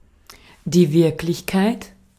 Ääntäminen
Synonyymit Realität Ääntäminen Tuntematon aksentti: IPA: /ˈvɪʁklɪçˌkaɪ̯t/ IPA: [ˈvɪɐ̯kʰlɪçˌkʰaɪ̯tʰ] Haettu sana löytyi näillä lähdekielillä: saksa Käännös Ääninäyte Substantiivit 1. reality US Artikkeli: der .